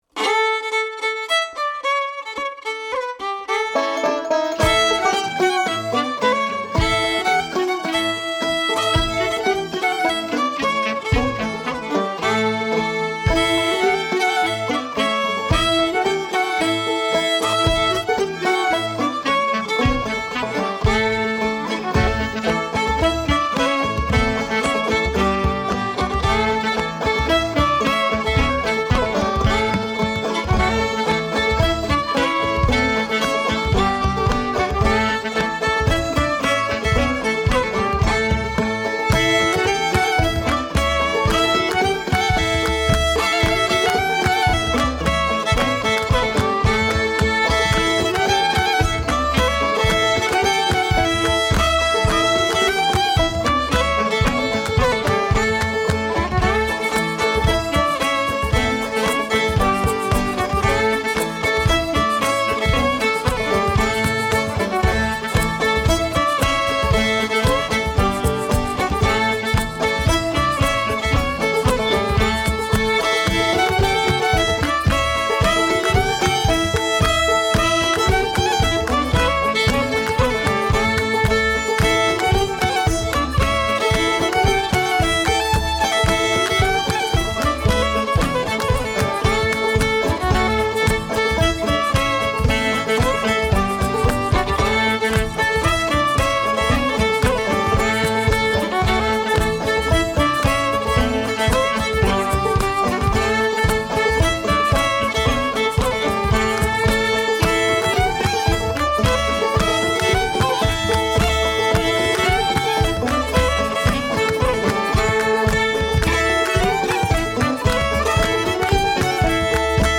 Old-Joe-Clark-mix-5-only-1-banjo-louder.mp3